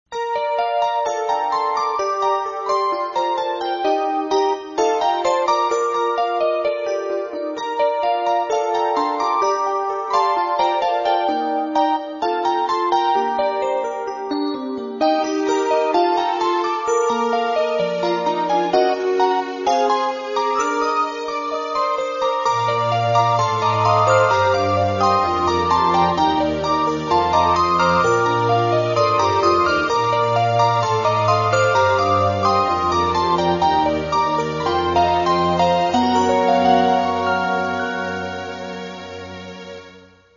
出会った直後のエレピアノ曲。
でもエレピもストリングスもちょっと薄いべ、これ……。